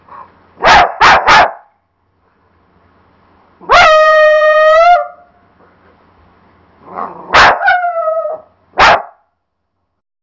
Cat_dog.mp3